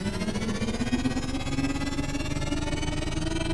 speed_up_3.ogg